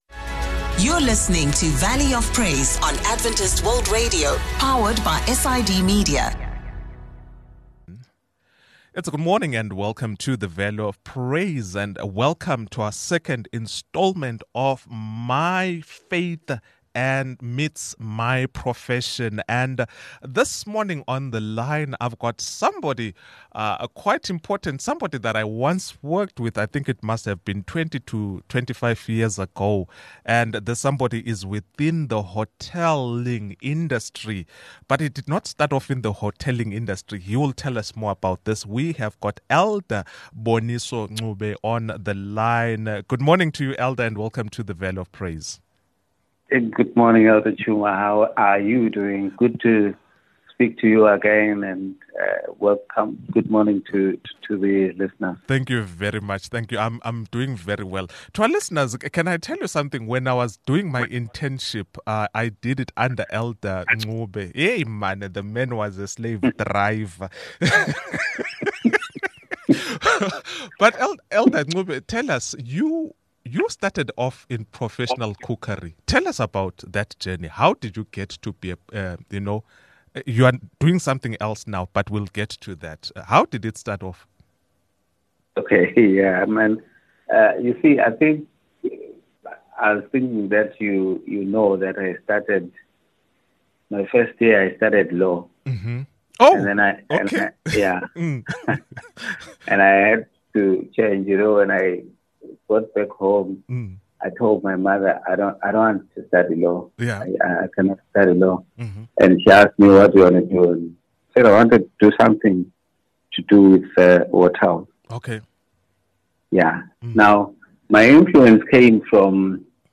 From overcoming obstacles to embracing faith in the workplace, we discuss it all. Get ready for a candid conversation on living out your faith in your profession.